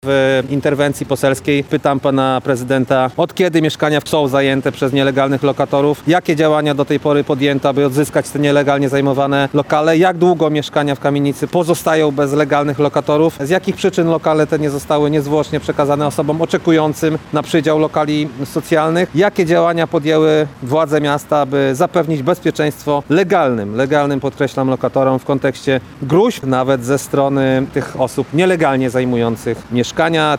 – mówił podczas konferencji prasowej poseł Bartłomiej Pejo.